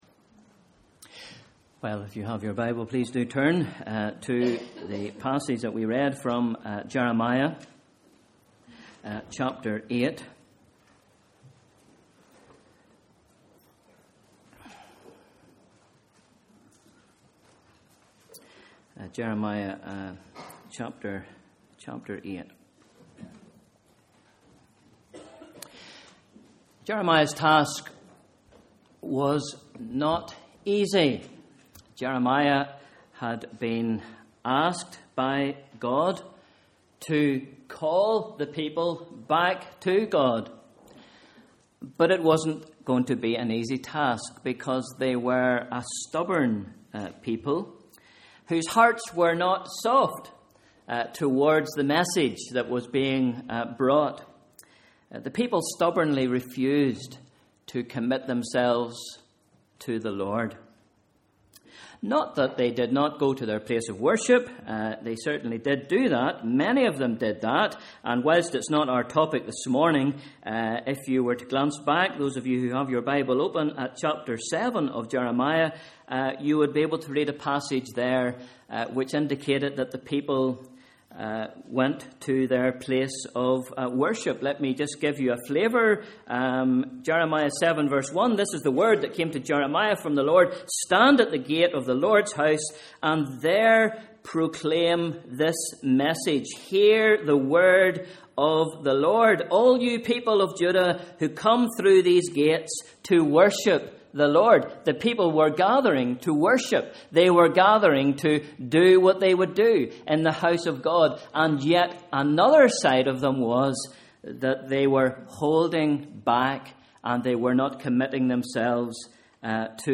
Bible Reading: Jeremiah 8:13-20 Sunday 20th October: Morning Service (Harvest)